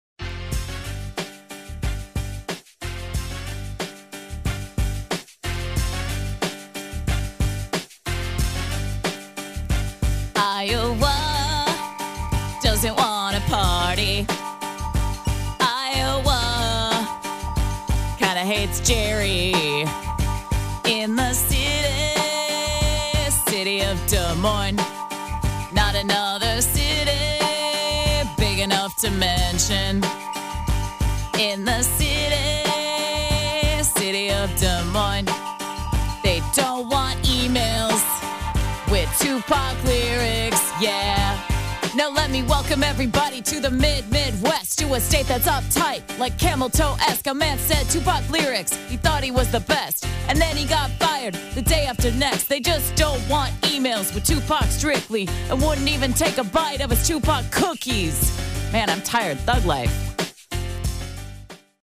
and even made a rap on their hatred.